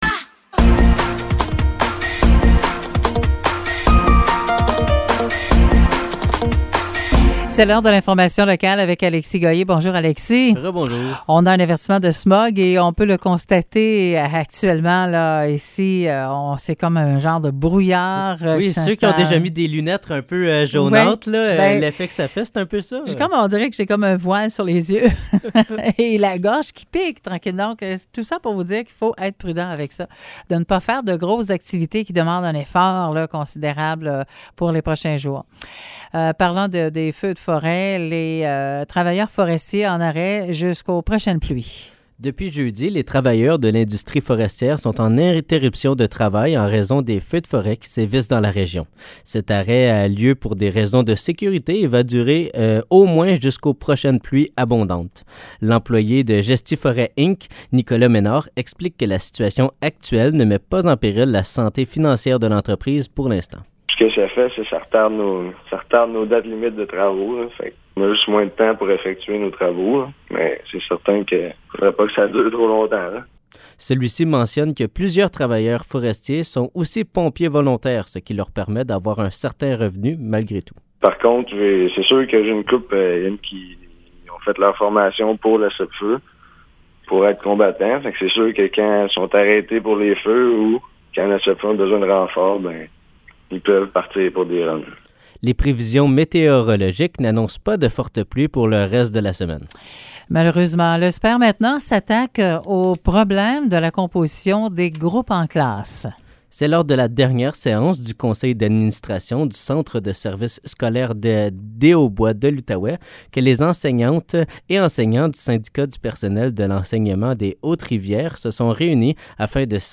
Nouvelles locales - 5 juin 2023 - 10 h
CHGA FM vous informe tout au long de la journée.